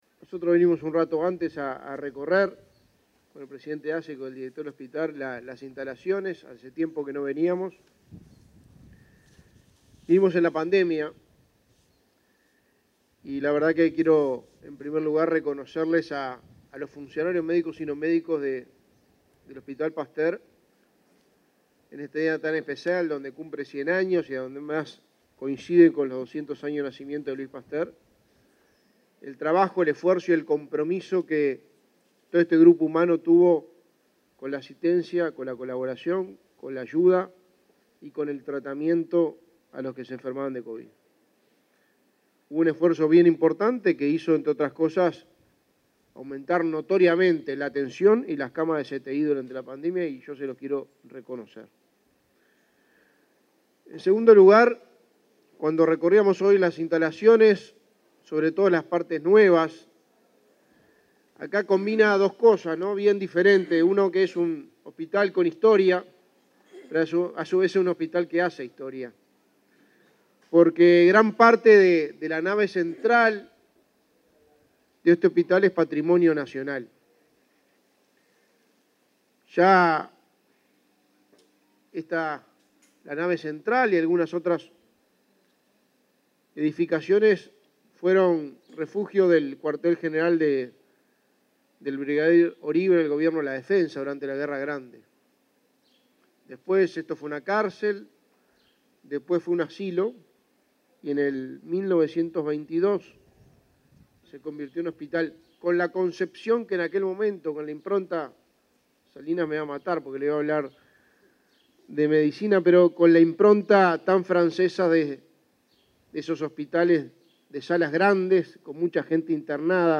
Conferencia de prensa por los 100 años del hospital Pasteur
La Administración de los Servicios de Salud del Estado (ASSE) realizó, este 22 de noviembre, el acto de celebración por los 100 años del hospital
Participaron del evento el secretario de Presidencia, Álvaro Delgado; el ministro de Salud Pública, Daniel Salinas, y el presidente de ASSE, Leonardo Cipriani.